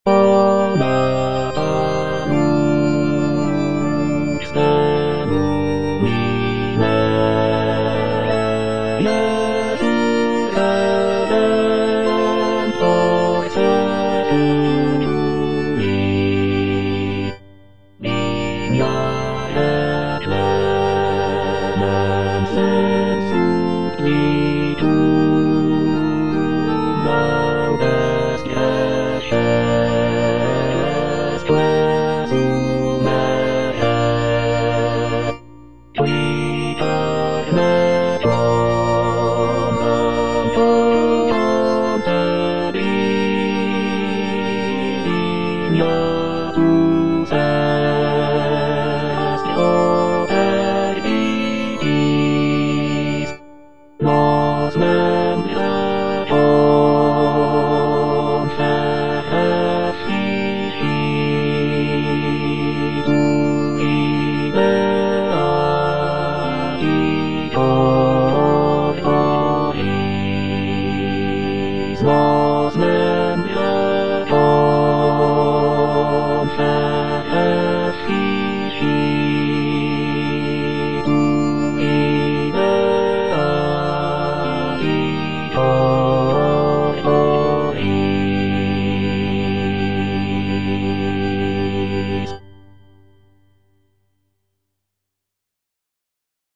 "O nata lux" is a sacred choral work composed by Thomas Tallis, a prominent English Renaissance composer. It is part of his larger work called "Cantiones Sacrae," which consists of Latin motets. "O nata lux" is a short but beautifully crafted piece, known for its serene and ethereal quality. The composition showcases Tallis' skillful use of polyphony, with overlapping vocal lines creating a rich and harmonically intricate texture. With its expressive melodies and delicate harmonies, "O nata lux" remains a beloved and frequently performed piece in the repertoire of sacred choral music.